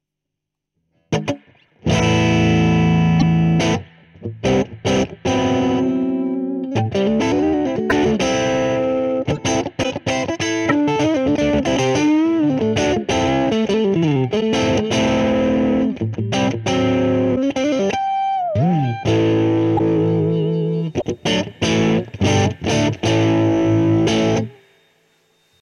HP Celestion : Sonne comme les meilleurs marshall mais en transo ! Très typé JCM800, mais en plus domptable et plus pratiquable à faible volume ..
Reverb12 Crunch1.mp3